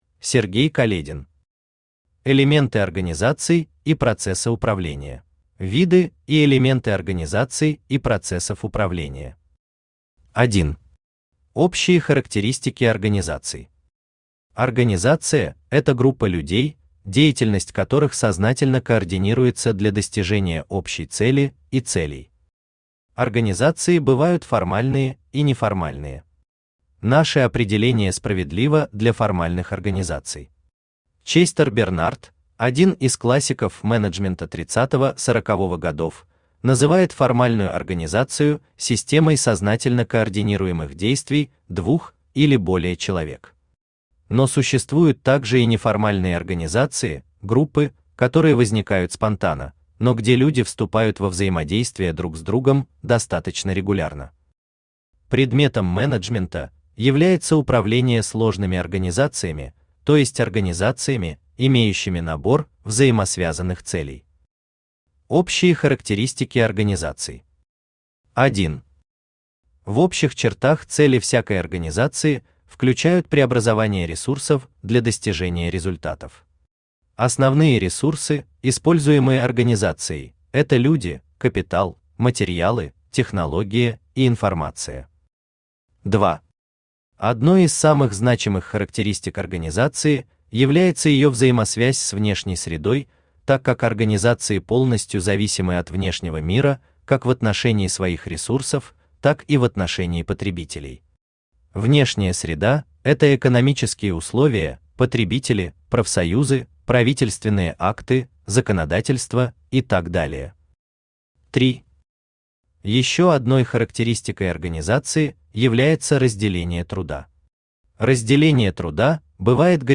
Аудиокнига Элементы организаций и процесса управления | Библиотека аудиокниг
Aудиокнига Элементы организаций и процесса управления Автор Сергей Каледин Читает аудиокнигу Авточтец ЛитРес.